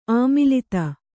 ♪ āmilita